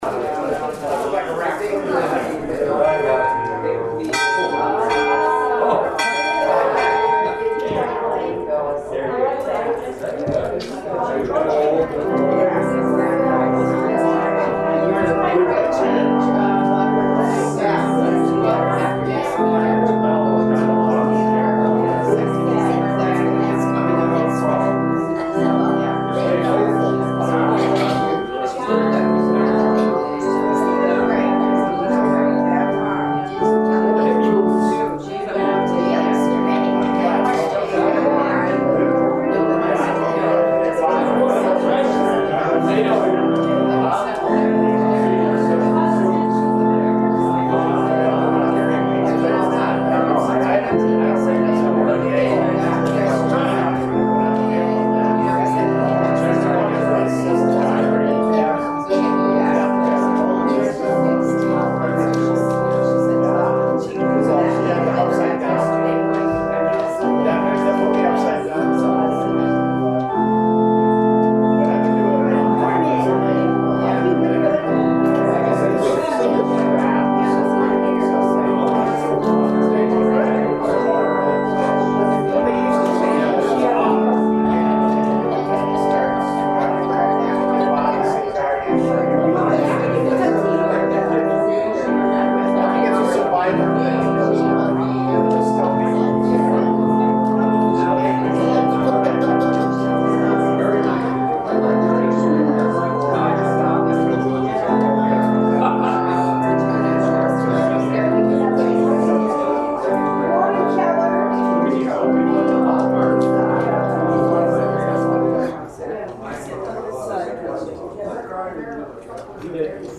Morning message